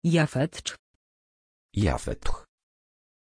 Pronunciation of Japheth
pronunciation-japheth-pl.mp3